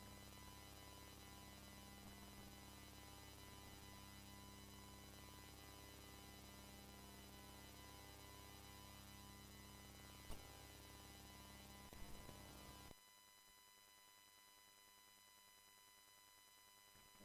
The Roland SC-8820 can be powered solely on USB, without the need for wall power, though this results in some USB bus noise with my setup, possibly because I typically have it connected to my laptop in two places at once through the USB connection and headphone jack.
I have a few MIDIs that crash the SC-8820, and on USB power, the crashing is actually audible as a change to the buzzing.
Note that I've raised the volume of this clip slightly so the change is easier to hear. The buzzing isn't typically this loud in practice.